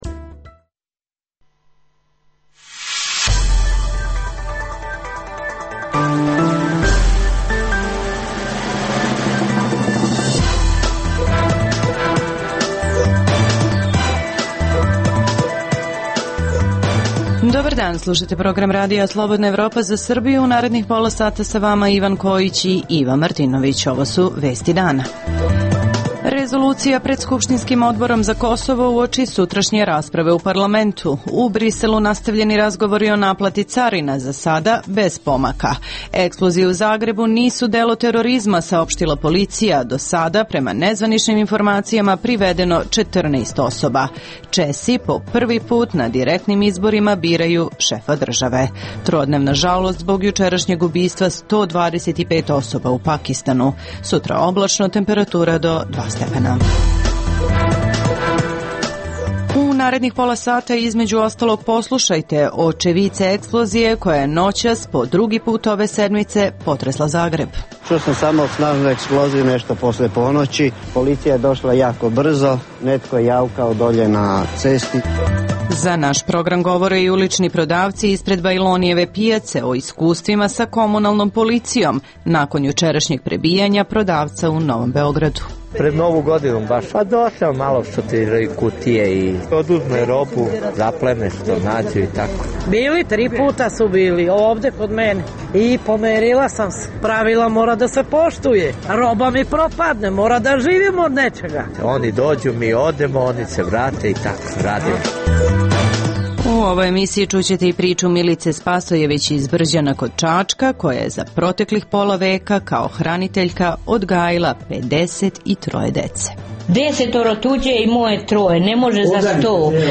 - U Briselu nastavljeni razgovori o naplati carina, za sada bez pomaka. - Eksplozije u Zagrebu nisu delo terorizma, saopštila policija. Čućete i izjave očevidaca.